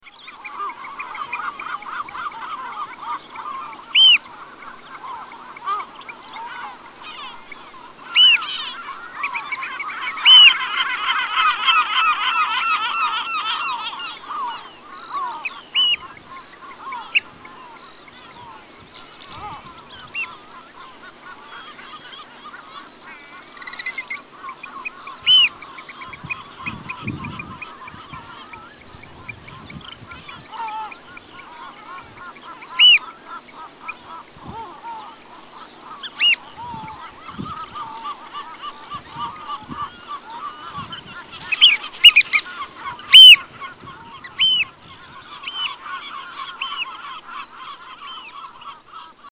Oystercatcher call at Skokholm Island, Pembrokeshire
Category: Animals/Nature   Right: Personal